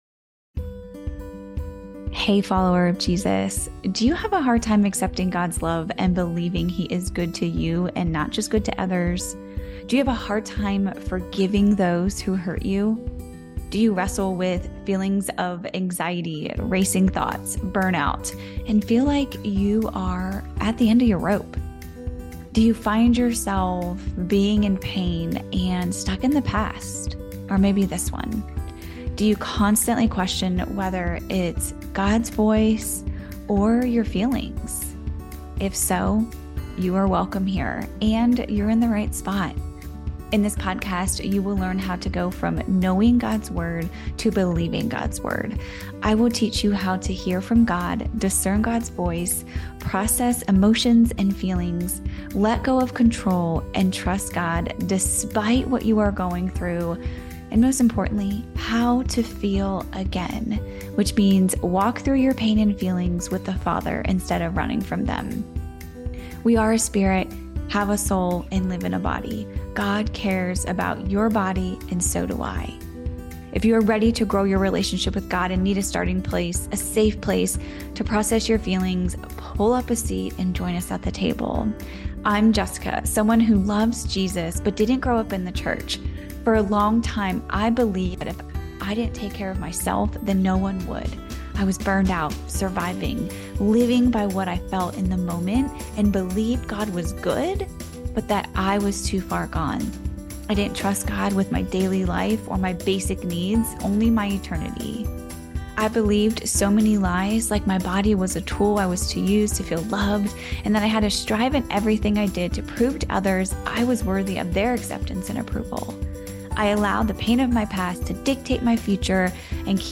LIVE Recording: Processing through Pain in a Way that Honors God